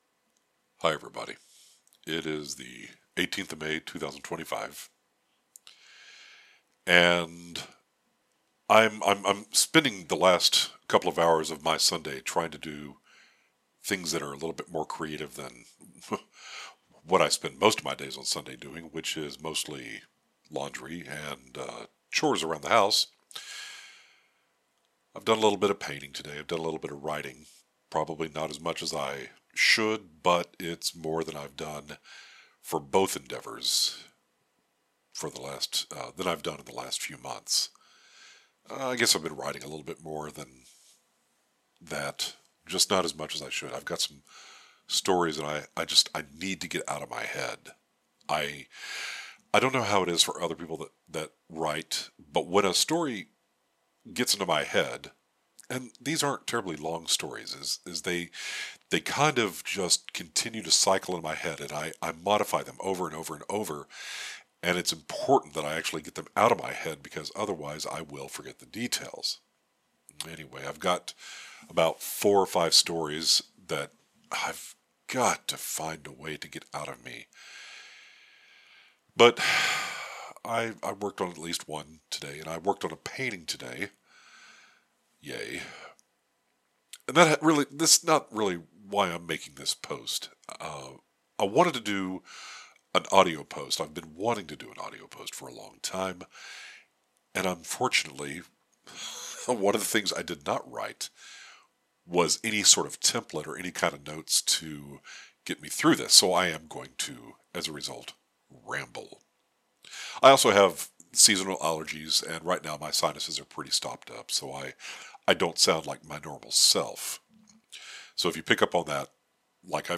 Welcome to my late Sunday afternoon ramblings of a mostly sane man with allergies, stopped up sinus’ and no script. Lots of pauses as the voices in my head tell me what to say next and the microphone is a cheap, headset mic.
Note: i didn’t edit out the pause at the start that’s used for noise reduction.
Also, i forgot that i was streaming SomaFM in the living room – so if you pick up some music in the background, that would be the ever popular Groove Salad helping out.